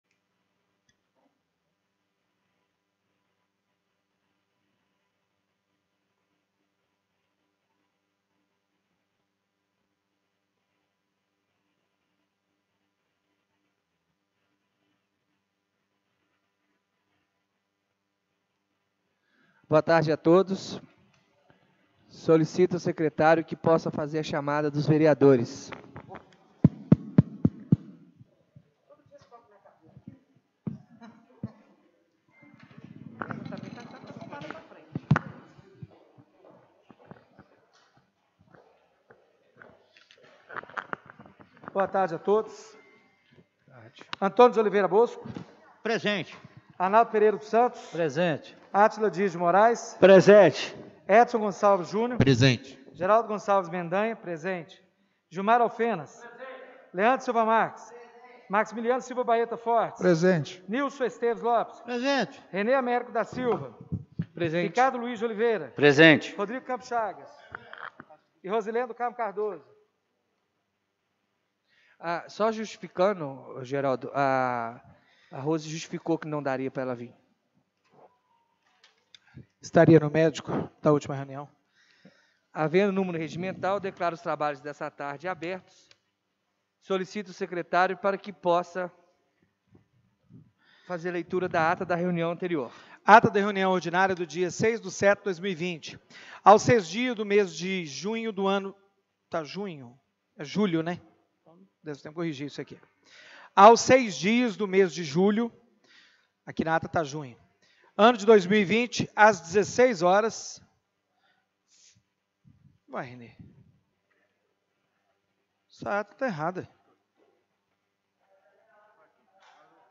Reunião Extraordinária do dia 08/07/2020